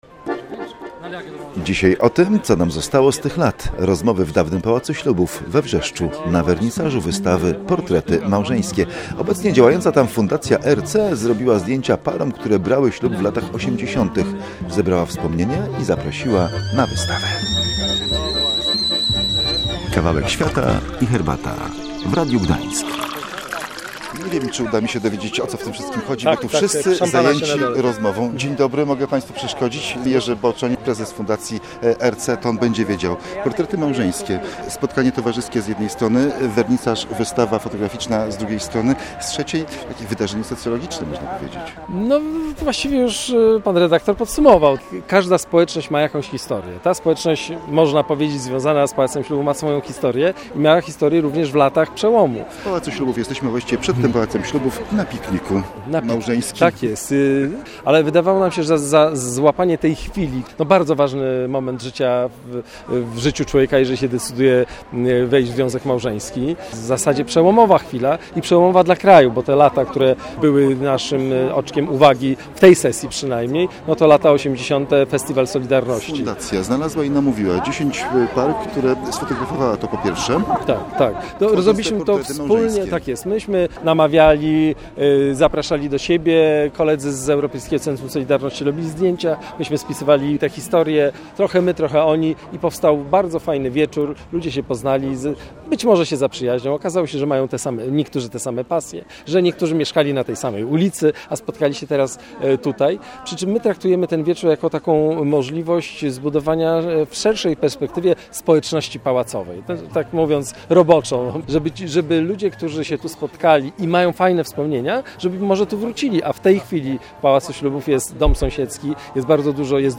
Rozmowy w dawnym Pałacu Ślubów we Wrzeszczu na wernisażu wystawy "Portrety Małżeńskie".